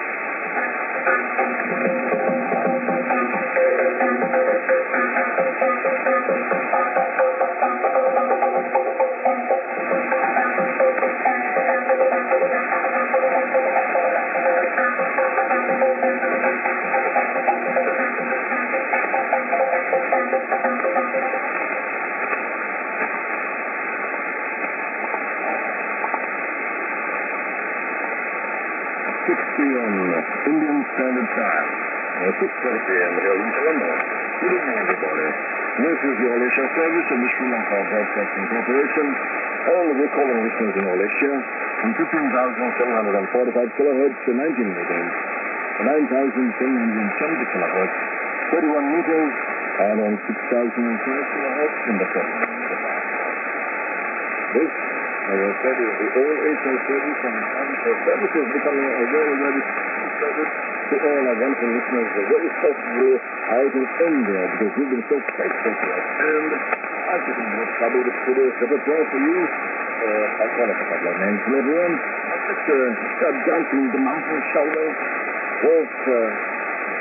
s/on 29'35":IS->TS->ANN(man:ID+SKJ)->　やっと開始のＩＳが良好に録音できました。